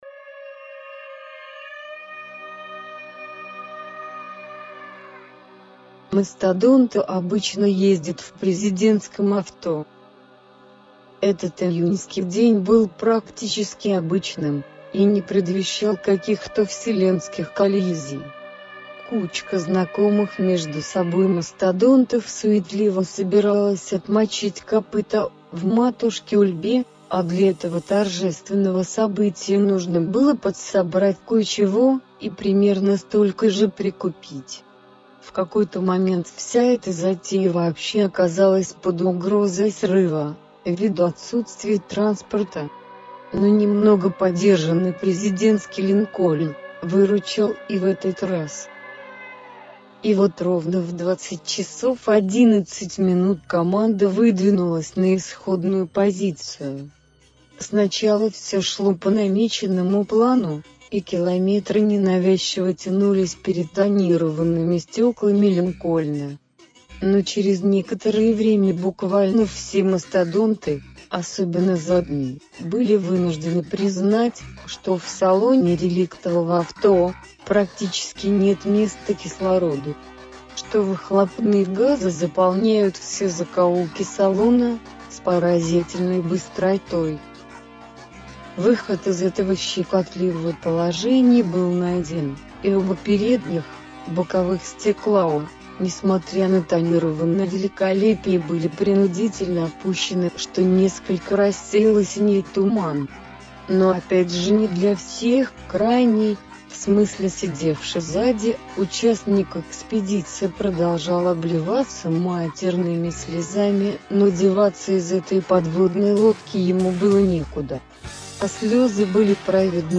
Музыкальные файлы записаны с помощью софта под общим названием ACID, речевые с помощью говорилки и голосового движка Алены.
аудиобред это уже не совсем музыкальное произведение, музыка здесь просто оттеняет практически прямой, и местами довольно высокий полет творческой мысли, как говорит один известный блоггер – местного автора. Собственно это пересказ вслух рассказа Мастадонты ездят в президентском авто